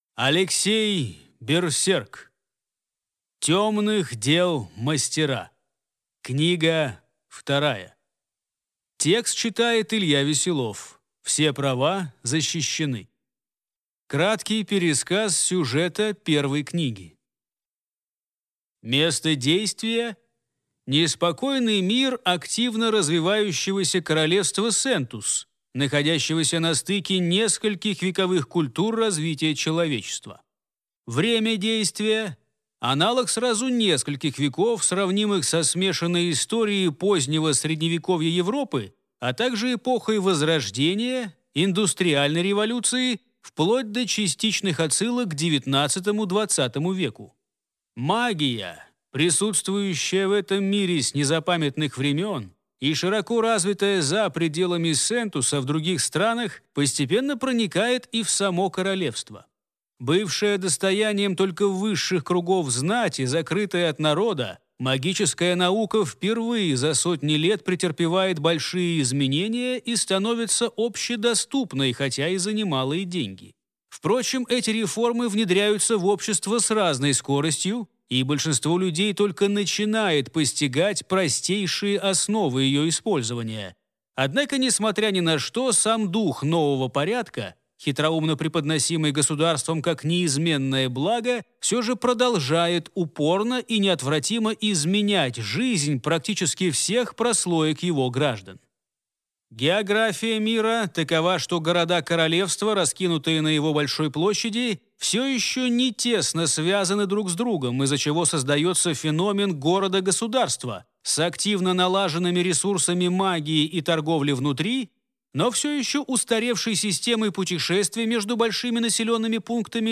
Аудиокнига Тёмных дел мастера. Книга вторая | Библиотека аудиокниг